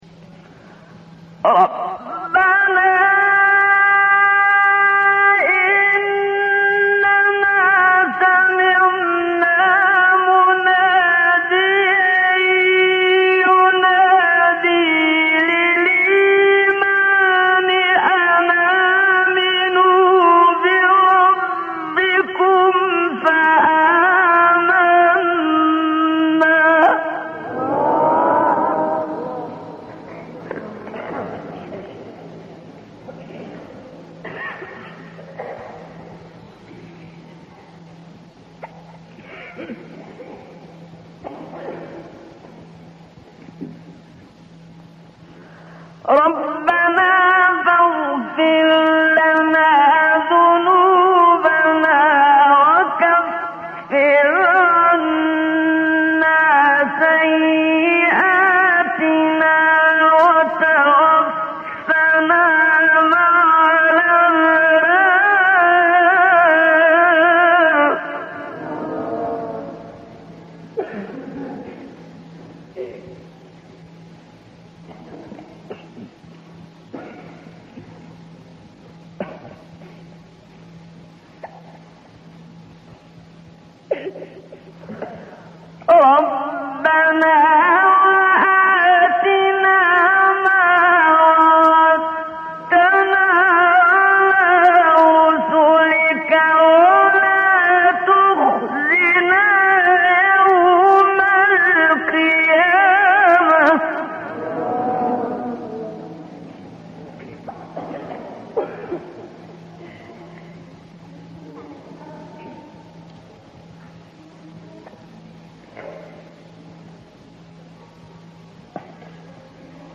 🔸در نغمه حزین و سوزناک صبا
و از دقیقه 1:24 سه‌گاه
و از دقیقه 6:30 راست